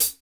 CYM X13 HA16.wav